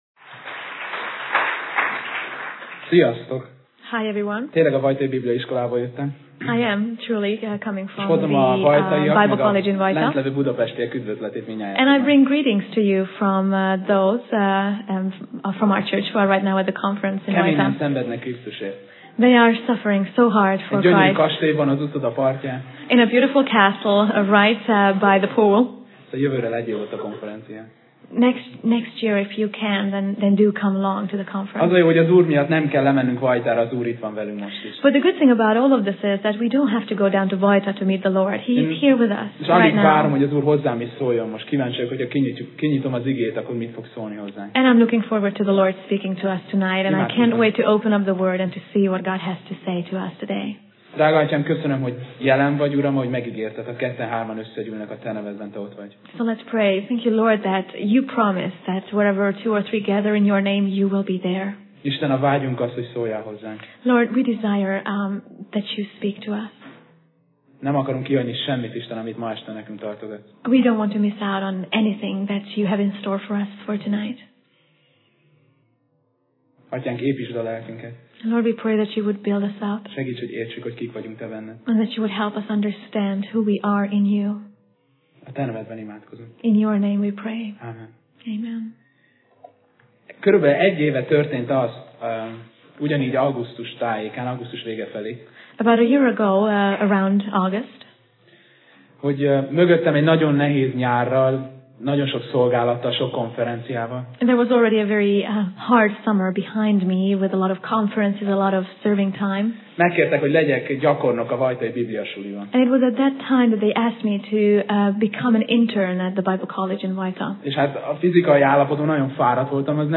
Tematikus tanítás Passage: Zsoltár (Psalm) 8:4-10 Alkalom: Szerda Este